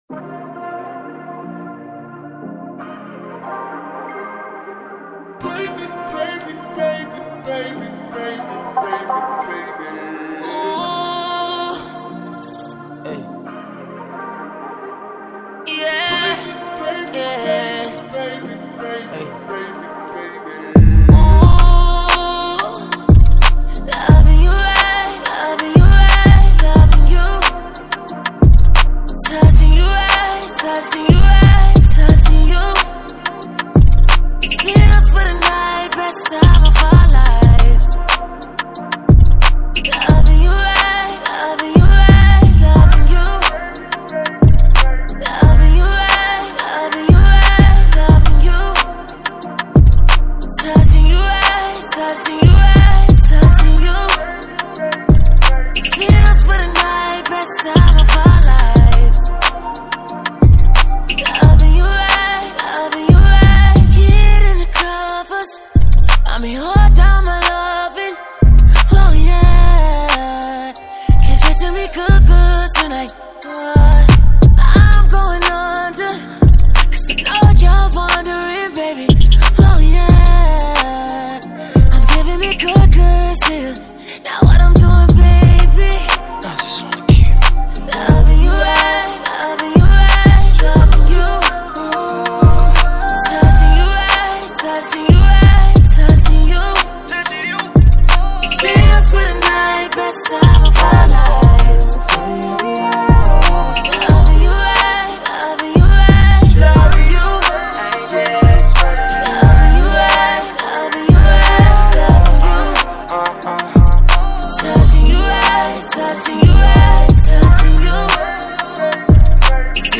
R&B track
showcases both artists’ vocal abilities and smooth delivery.
melodic R&B beat with soulful vocals